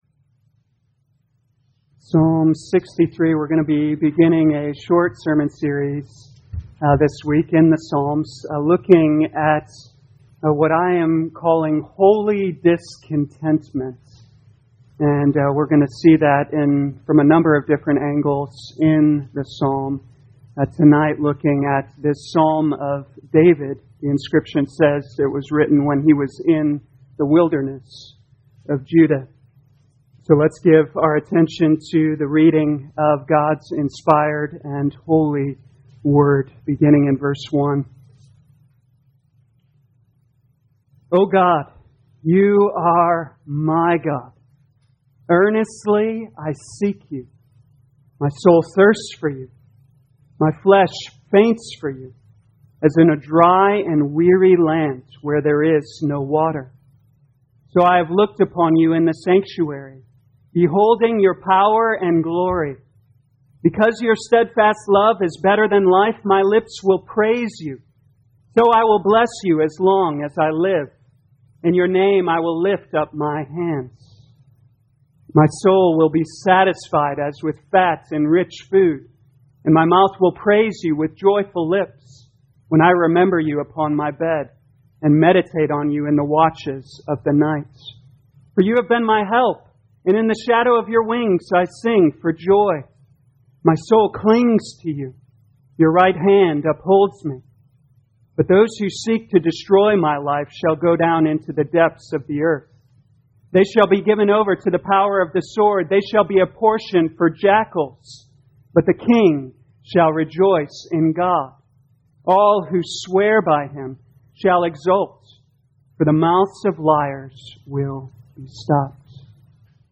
2021 Psalms Discontentment Evening Service Download